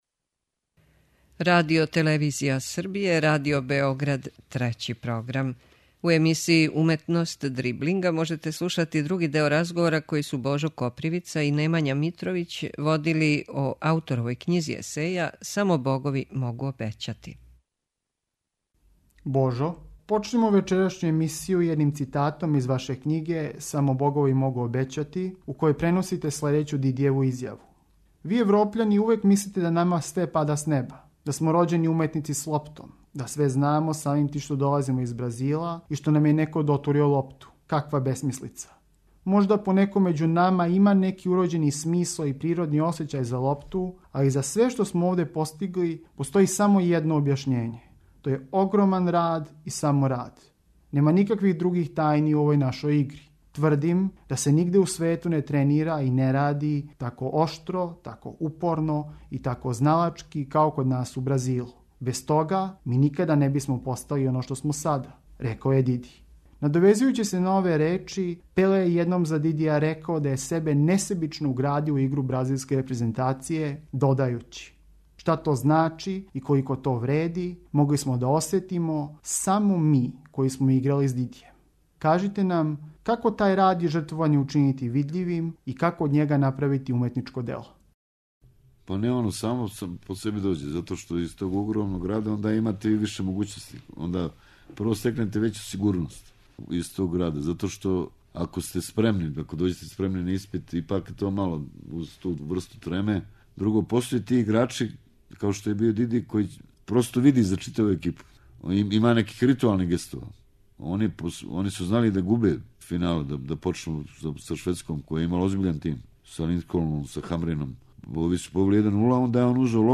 Повод за разговор са нашим чувеним есејистом је ново издање његове књиге Само богови могу обећати која се крајем 2014. појавила у издању Геопоетике.